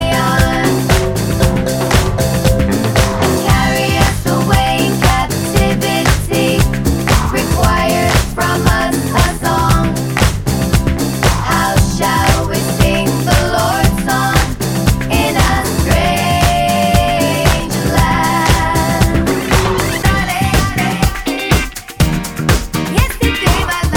Duet Disco 3:43 Buy £1.50